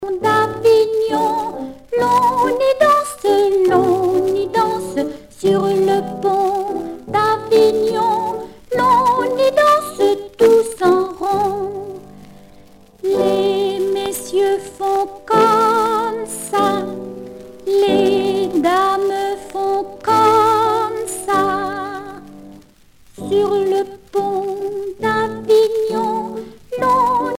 Enfantines - rondes et jeux
Pièce musicale éditée